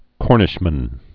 (kôrnĭsh-mən)